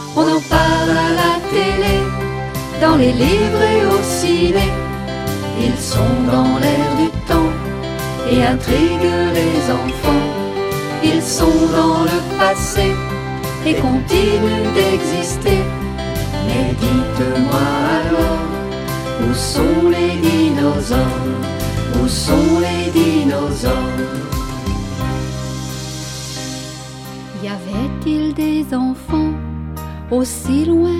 Cet album propose un conte musical et un conte pédagogique.
(Chanson)